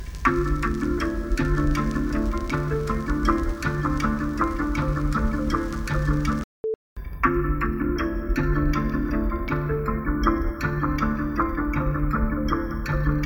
it seemed to reduce crackle, (I only tried it briefly).